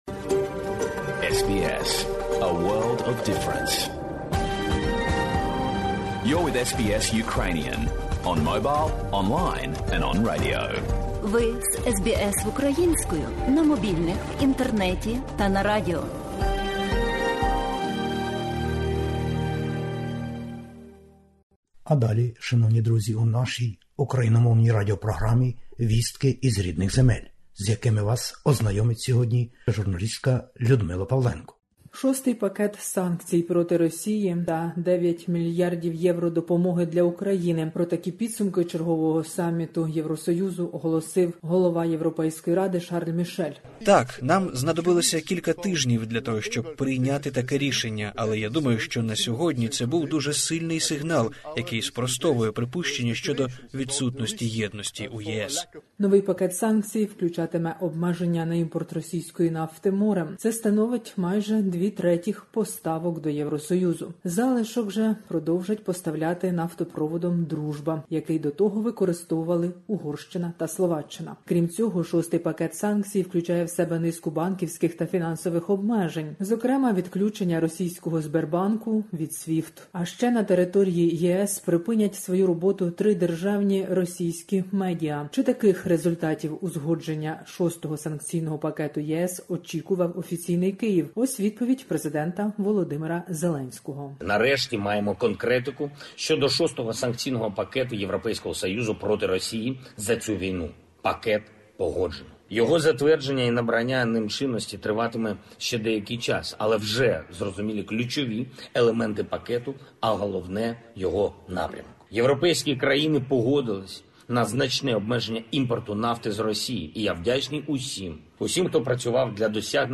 Добірка новин із героїчної України. ЄС - Україна: допомога, майбутнє членство і рішення на підтримку Української держави.